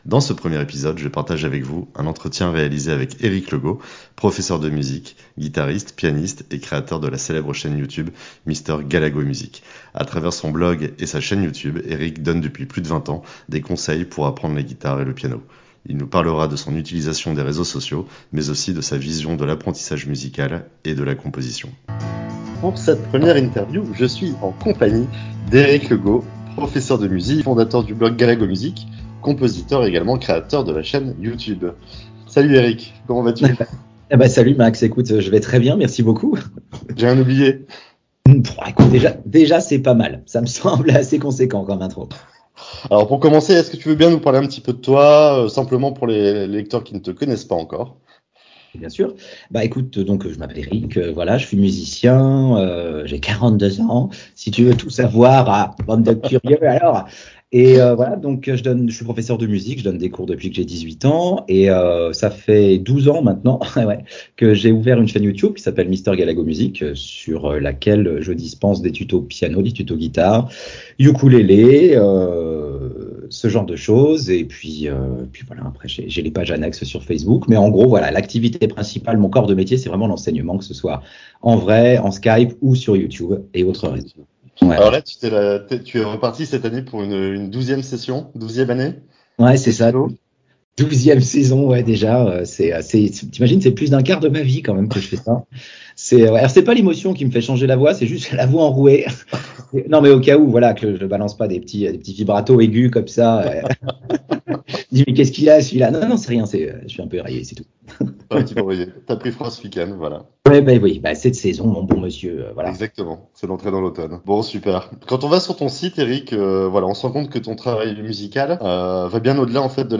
L’interview t'a plu ?